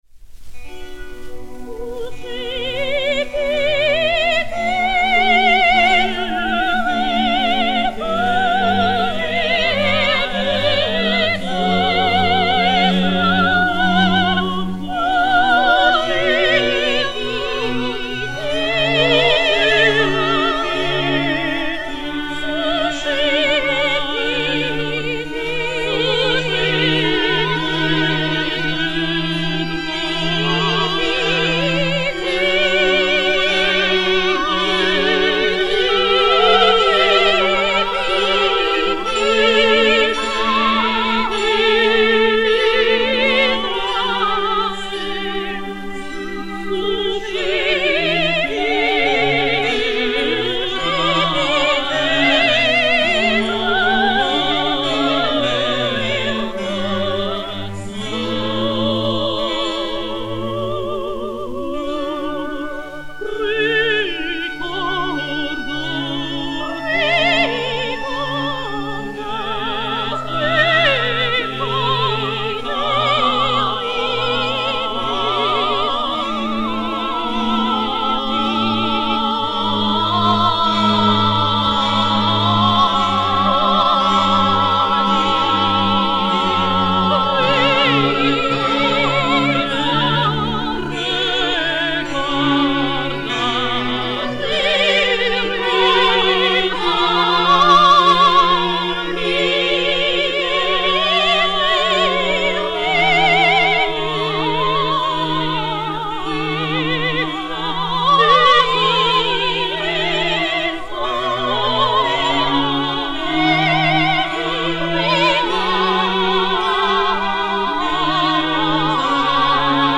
soprano français
hautbois d'amour
Orchestre Symphonique
CPTX 776, enr. au Théâtre des Champs-Elysées le 23 février 1948